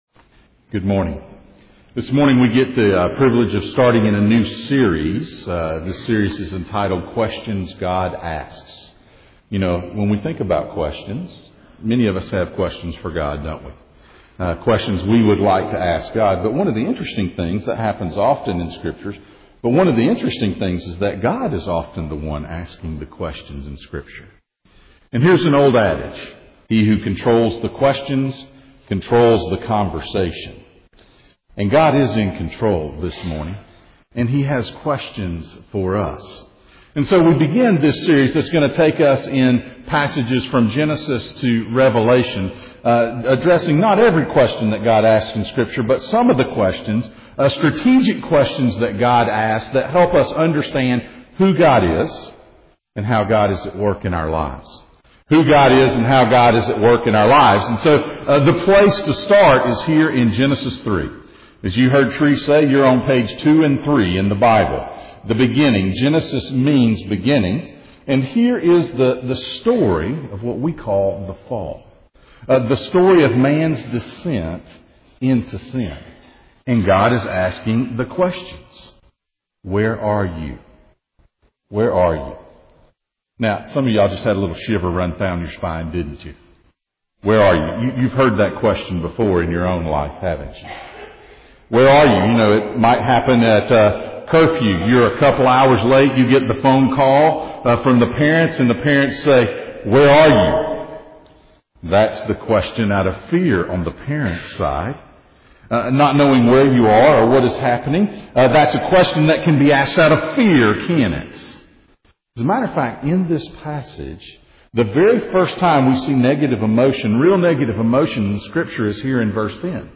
Questions God Asks Passage: Genesis 3:1-10 Service Type: Sunday Morning « Transformation Hoodwinked